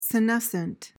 PRONUNCIATION:
(si-NES-uhnt)